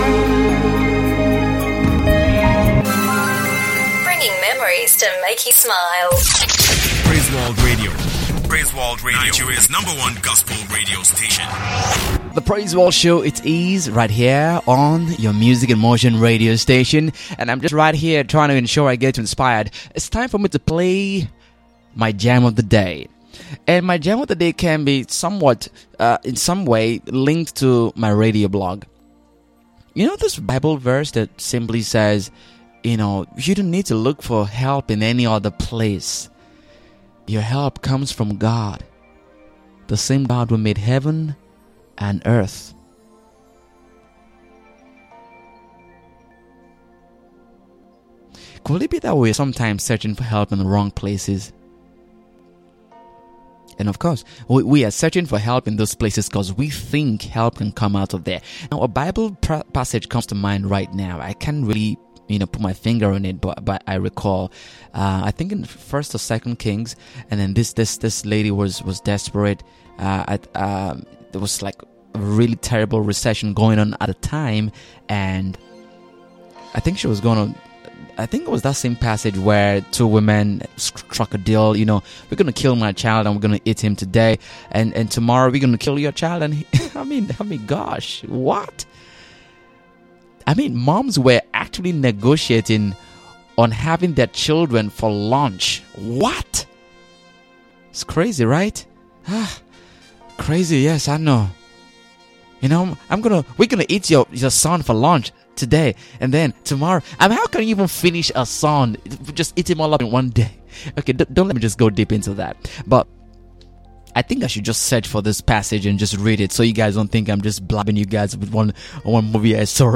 The Praiseworld Show airs every weekday on Praiseworld Radio from 10am-12noon.